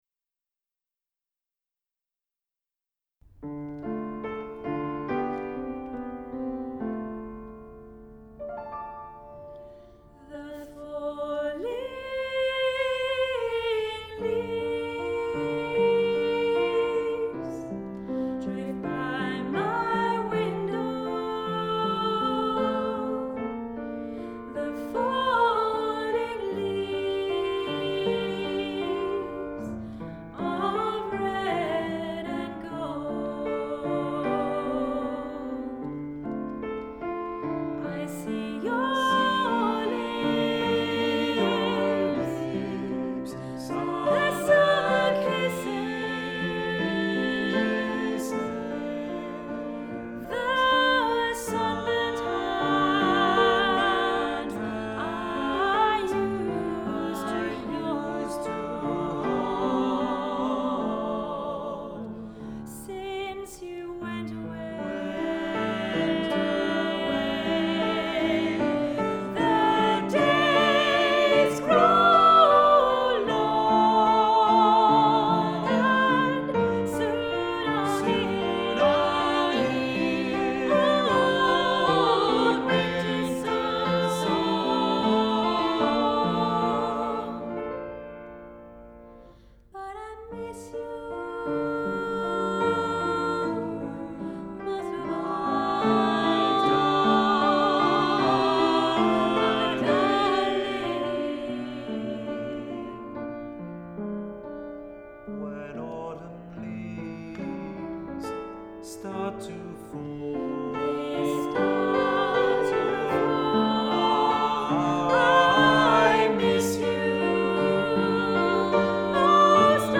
Voicing: Choir